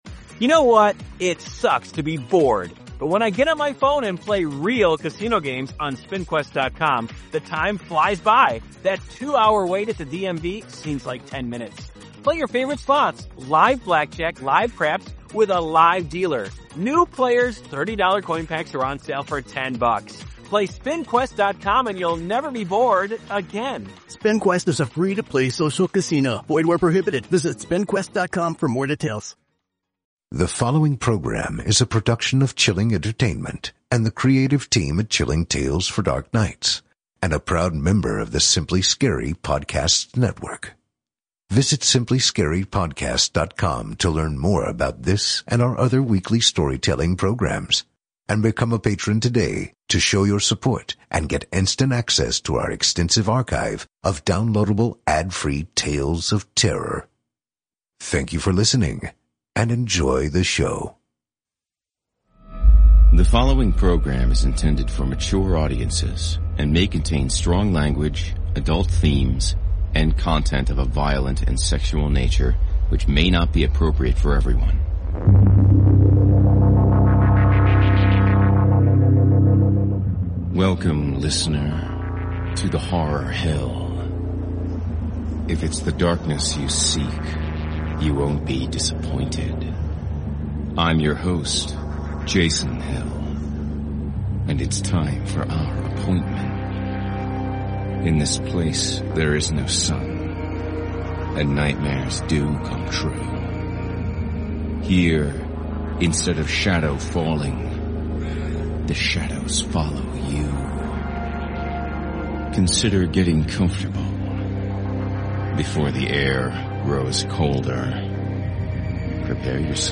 A Horror Anthology and Scary Stories Series Podcast